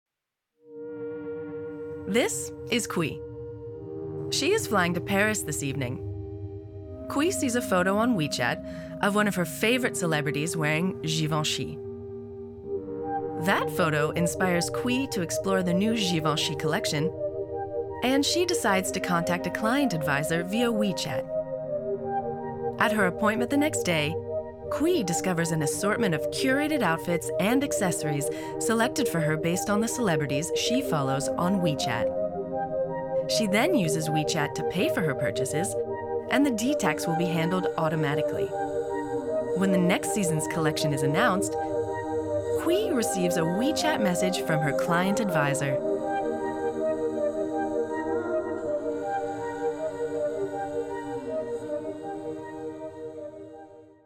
Voix off
Bandes-son
Je suis originaire de l'etat de l'Ohio, avec un accent en anglais americain.
J'ai une voix qui commmunique naturellememt et qui est agreable a ecouter.
18 - 100 ans - Contralto Mezzo-soprano